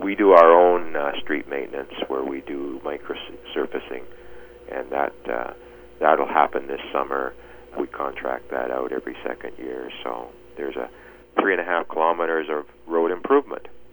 Mayor Toyota also says the Town of Creston also has some work planned.